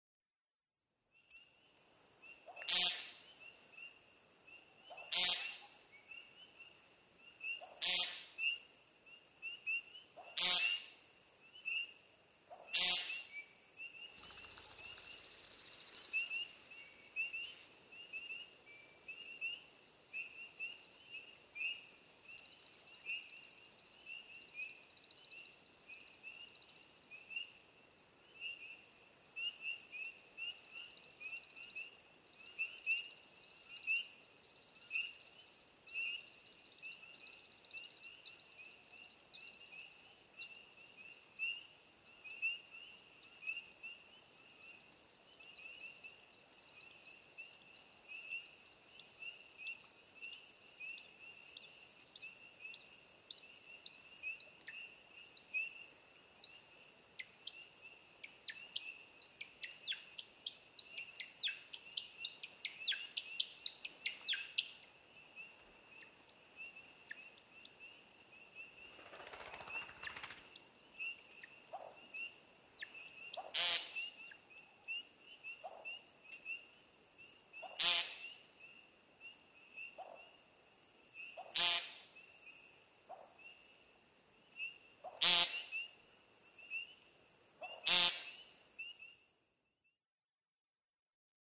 American woodcock
Nasal peents from the ground arena, followed by his "skydance," an aerial display involving both mechanical wing sounds and a vocal song. Adirondack Mountains, New York.
536_American_Woodcock.mp3